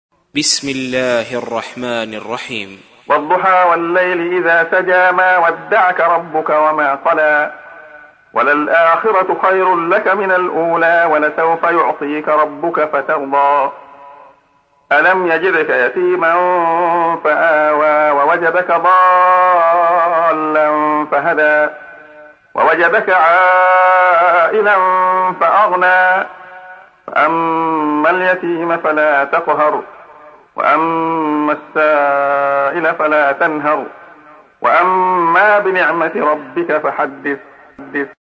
تحميل : 93. سورة الضحى / القارئ عبد الله خياط / القرآن الكريم / موقع يا حسين